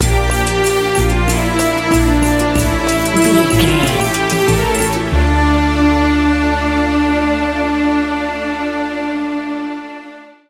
Aeolian/Minor
D
World Music
percussion
congas
bongos
djembe
kalimba
talking drum
marimba